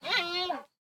sounds / mob / panda / idle4.ogg